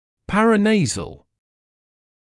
[ˌpærə’neɪzl][ˌпэрэ’нэйзл]околоносовой; параназальный